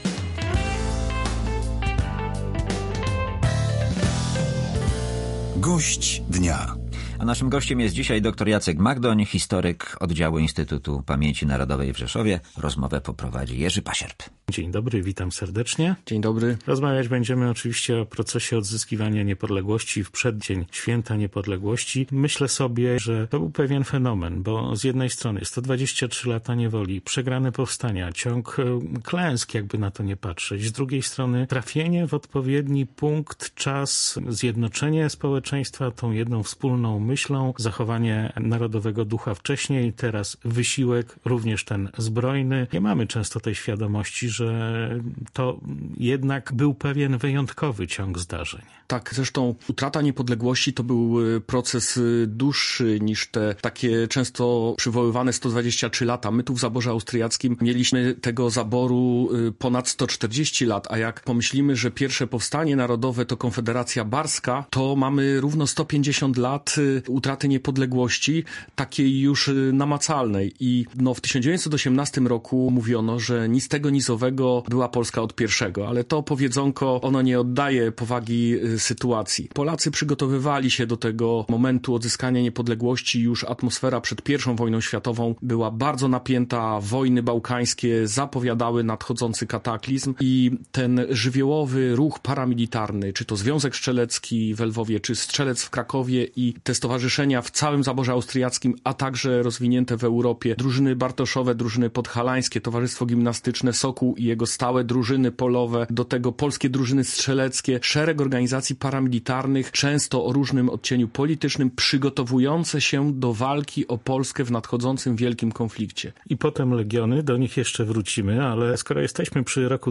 Audycje • Wydarzenia historyczne związane z kolejną rocznicą odzyskania przez nasz kraj niepodległości były tematem rozmów z gościem Polskiego Radia